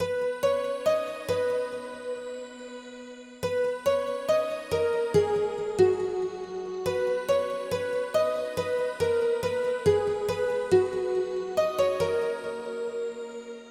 嘻哈鼓循环
描述：带有鼓与贝斯的嘻哈风格
Tag: 160 bpm Hip Hop Loops Drum Loops 1.01 MB wav Key : Unknown